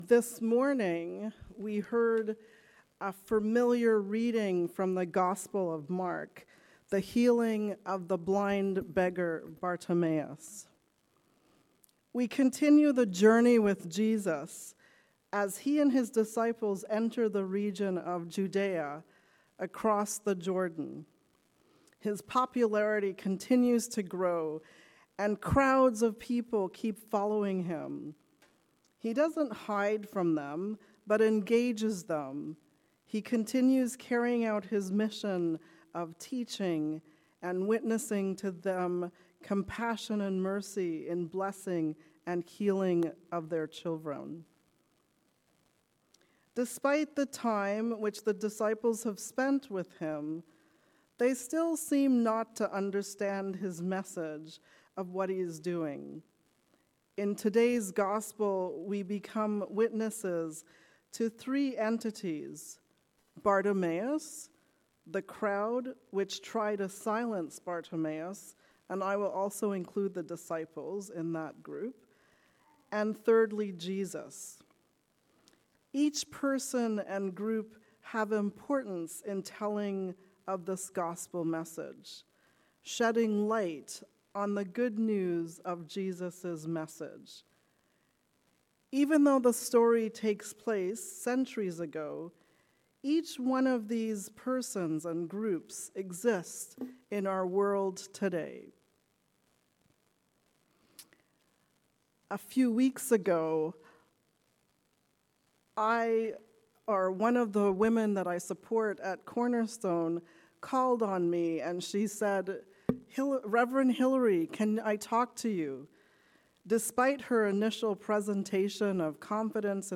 A sermon on Mark 10:46-52